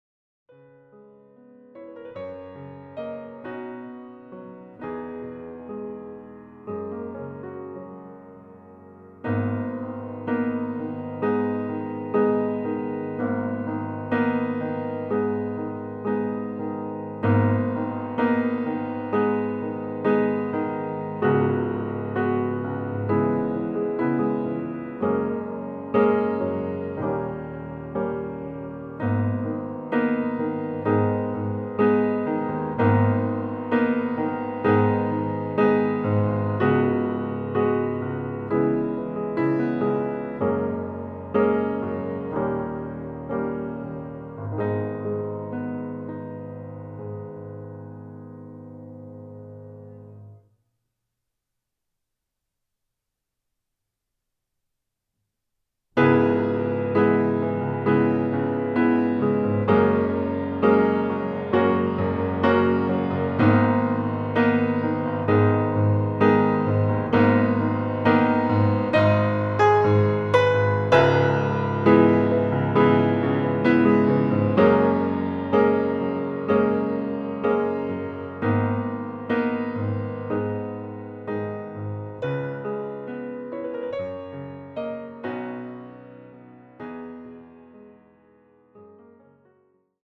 Klavierversion
• Art: Flügel Einspielung
• Das Instrumental beinhaltet NICHT die Leadstimme
Klavier / Streicher